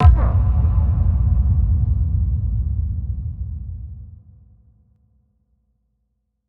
Index of /musicradar/cinematic-drama-samples/Impacts
Impact 01.wav